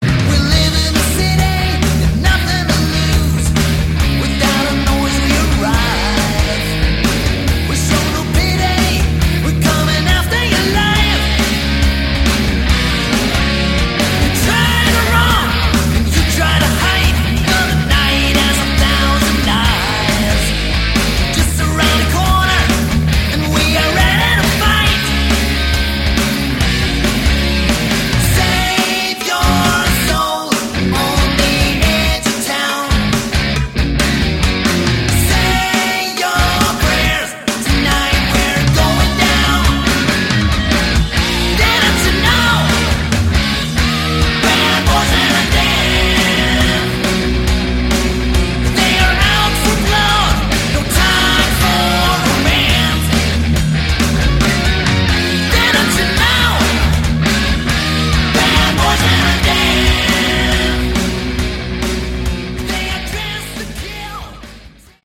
Category: Hard Rock
lead vocals, backing vocals, lead guitar, rhythm guitar
bass, backing voclas
drums, percussion, backing vocals